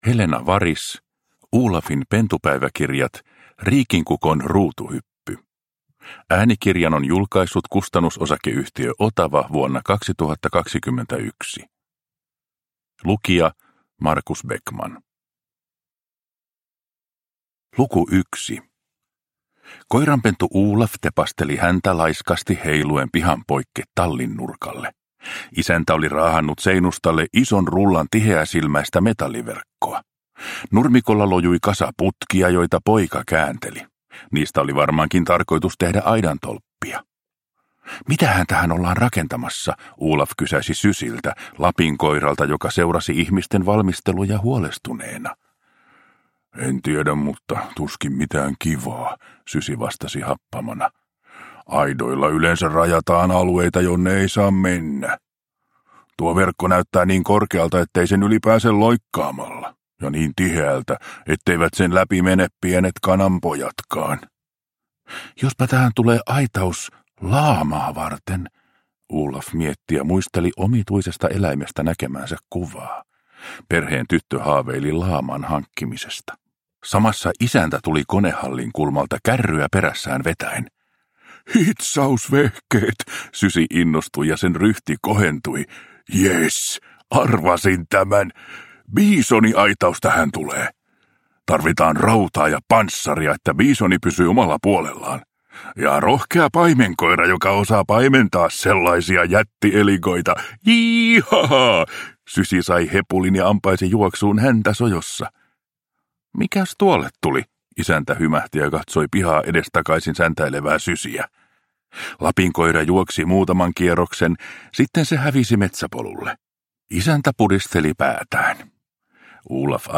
Olafin pentupäiväkirjat - Riikinkukon ruutuhyppy – Ljudbok – Laddas ner